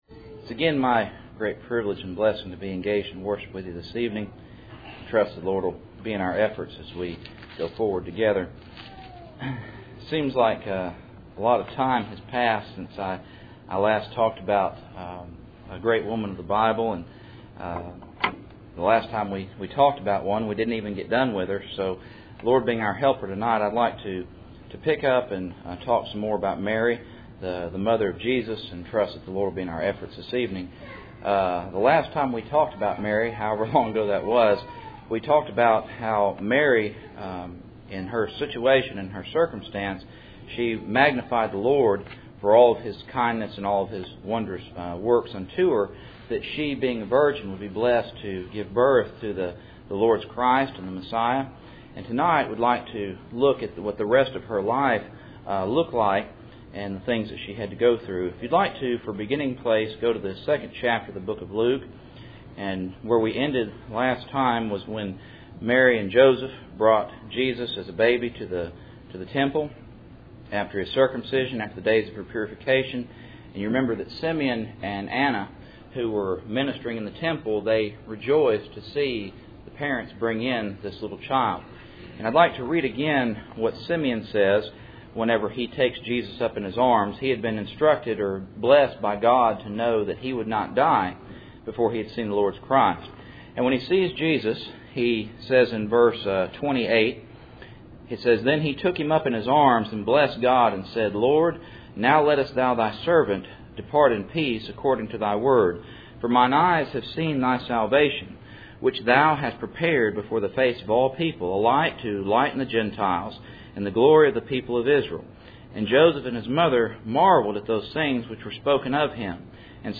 Cool Springs PBC Sunday Evening